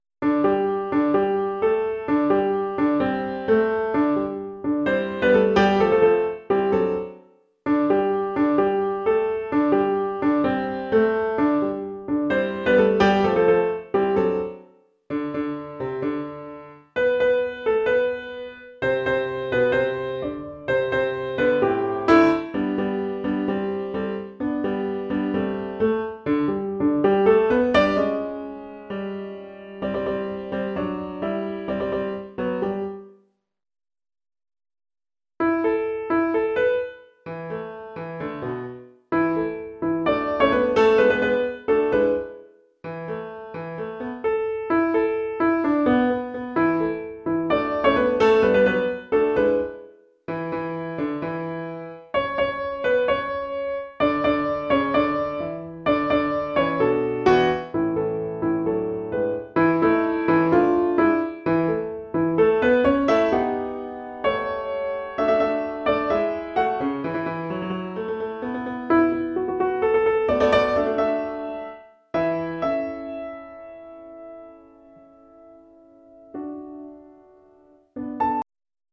Lockdown Practice Recordings
Comedy Tonight sop (short rest)